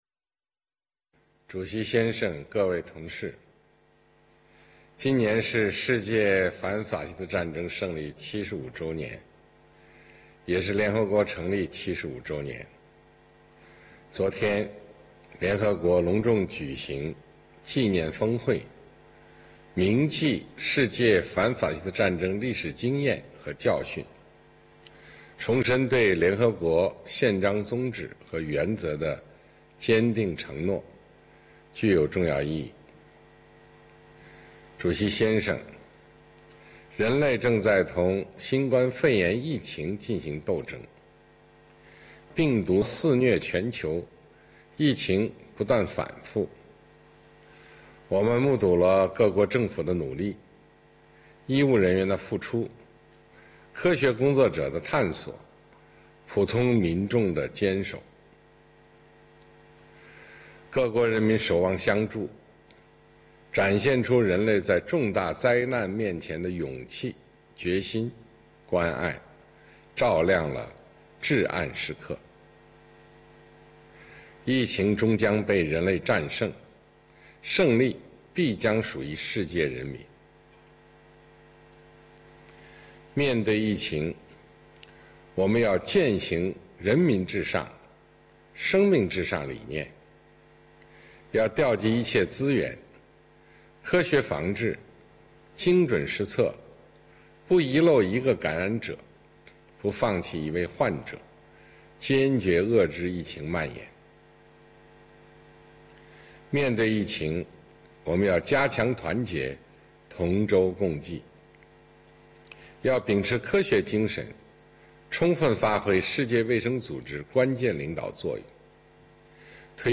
习近平在第七十五届联合国大会一般性辩论上的讲话